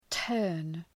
Προφορά
{tɜ:rn}